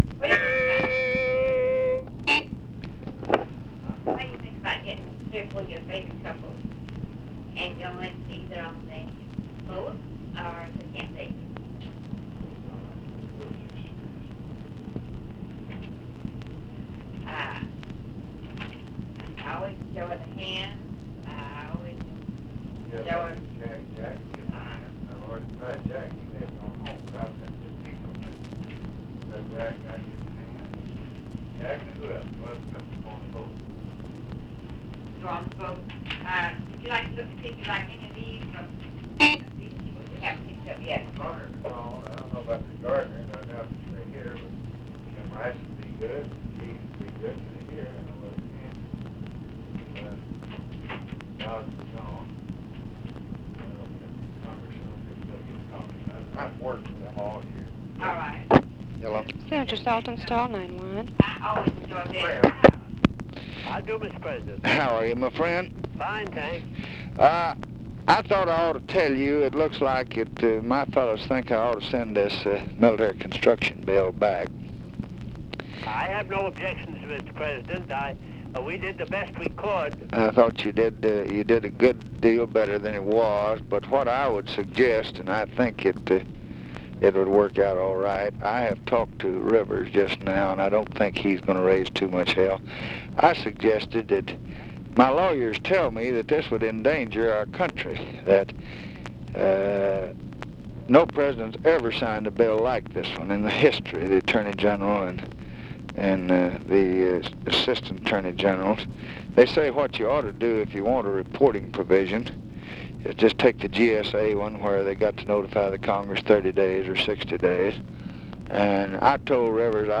Conversation with LEVERETT SALTONSTALL and OFFICE CONVERSATION, August 21, 1965
Secret White House Tapes